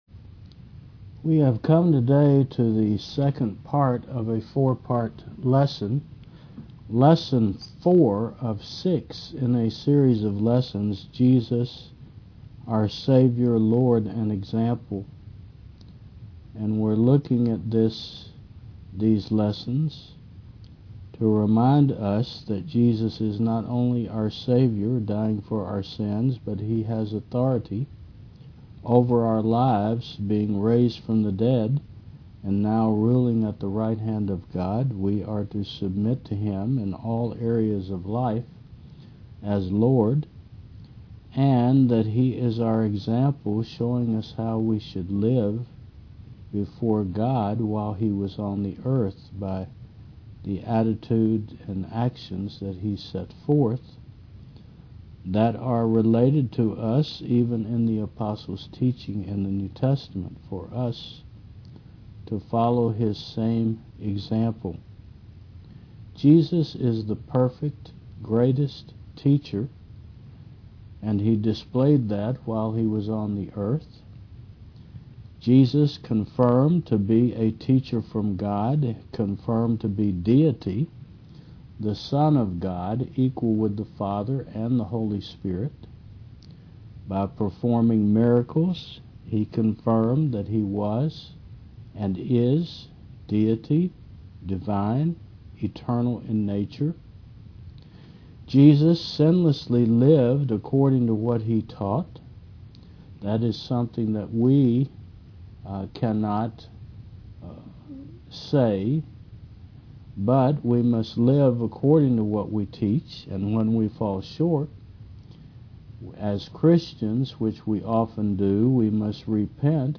2025 Jesus Our Savior Lord and Example v2 4 The Greatest Teacher 2 Preacher